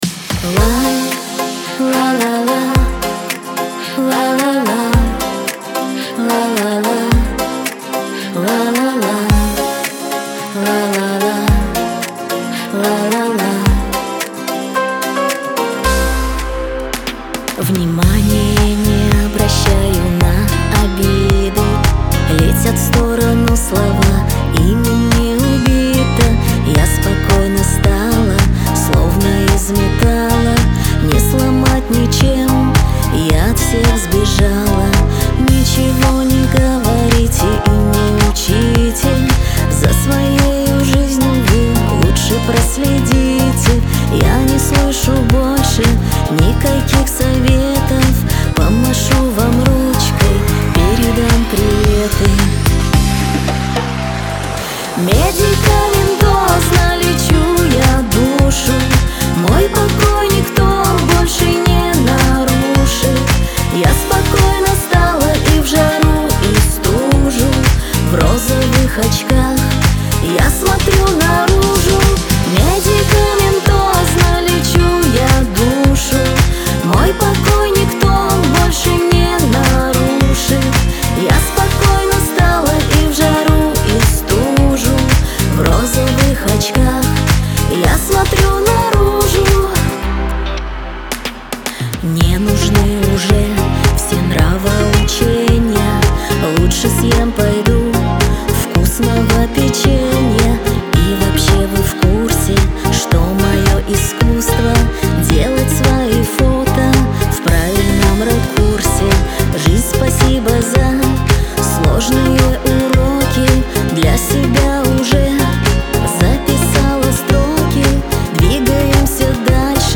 ХАУС-РЭП
грусть